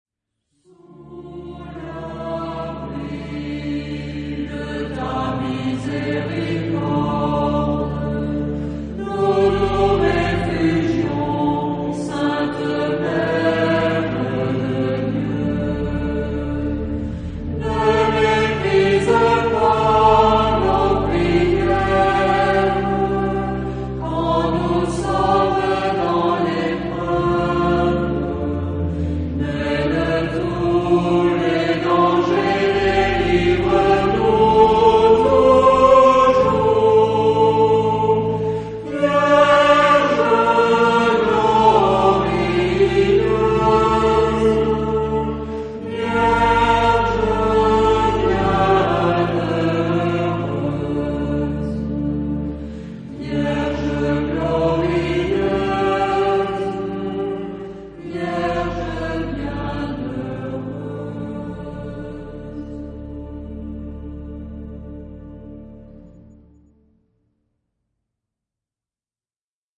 Genre-Style-Form: Prayer
Mood of the piece: adoring
Type of Choir:  (1 unison voices )
Instruments: Organ (1)
Tonality: D minor